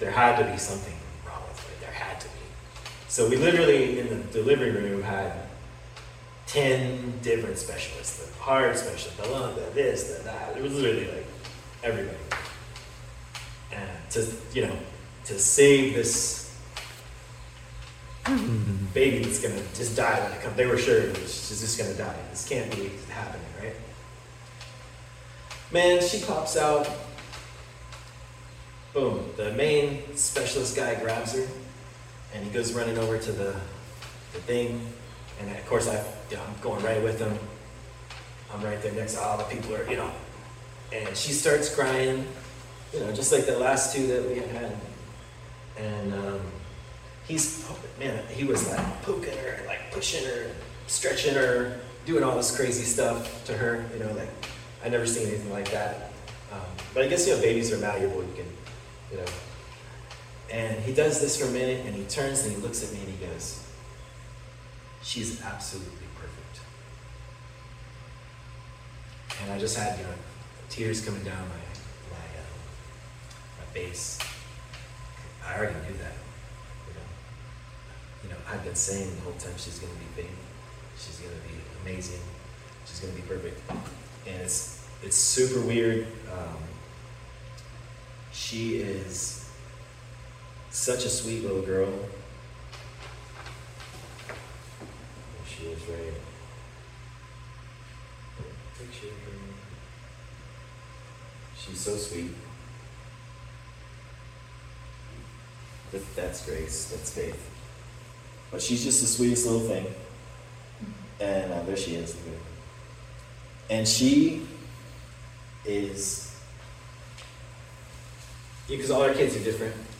Saturday night and Sunday Morning worship service  January 24-25, 2026
Sunday morning Worship and Ministry